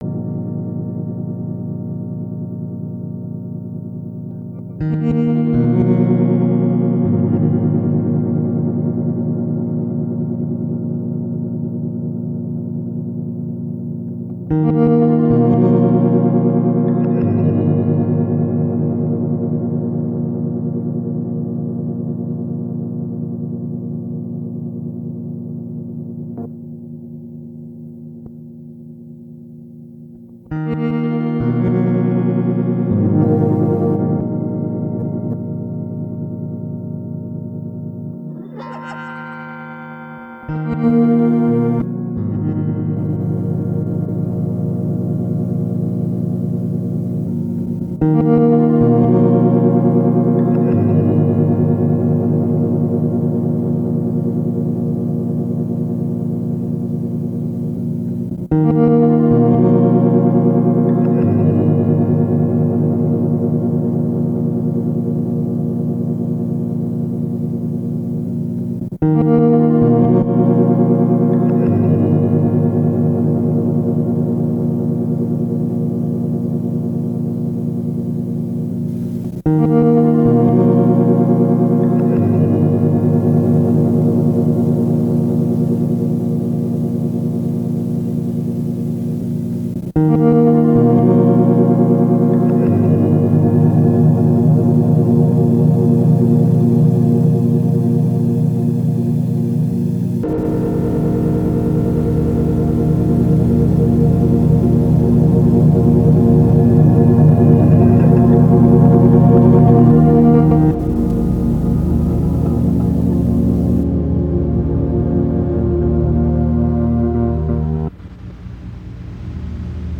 a hybrid between edgy improv takes and deep IDM-ish grooves
All this was done on a live setup without a laptop.